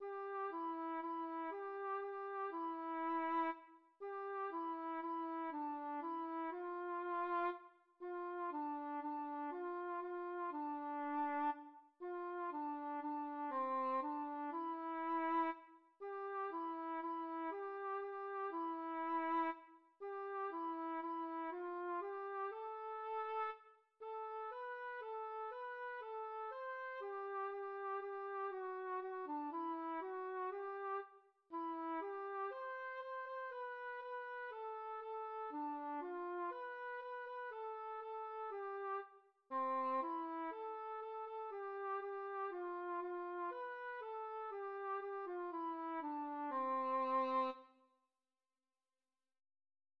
Műfaj magyar könnyűzenei dal
Hangfaj dúr
A kotta hangneme C dúr